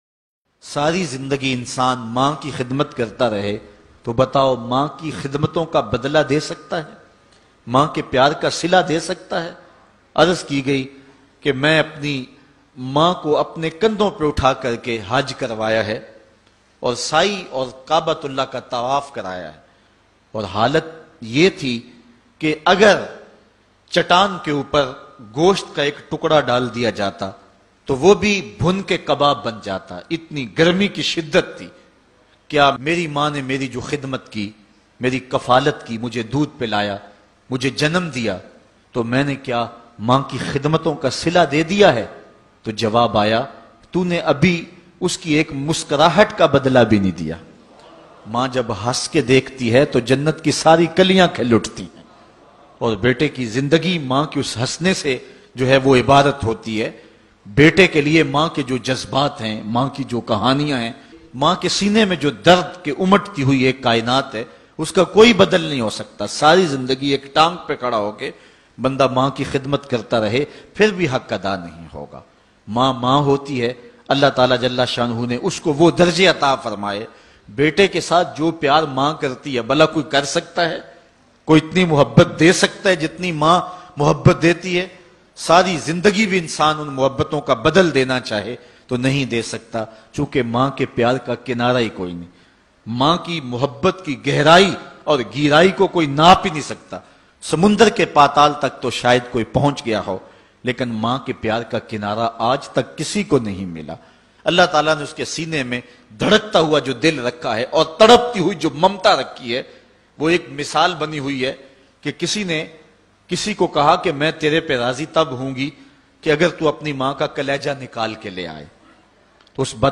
Emotional Bayan